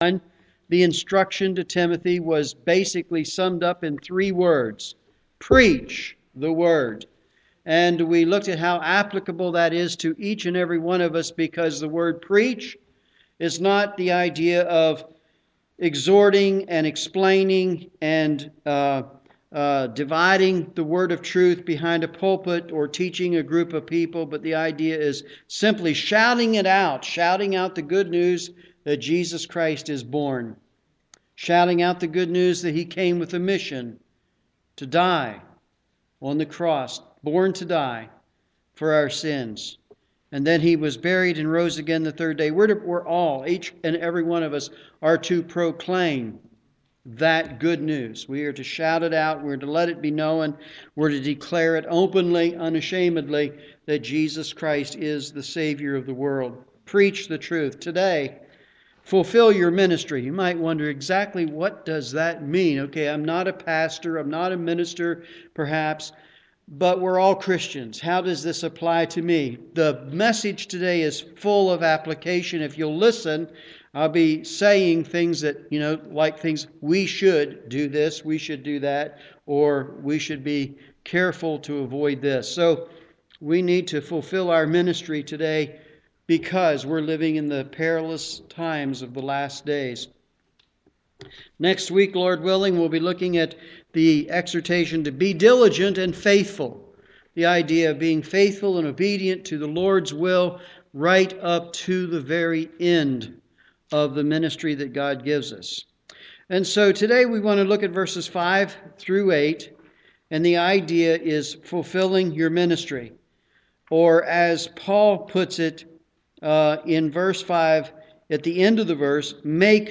Morning Service
Sermon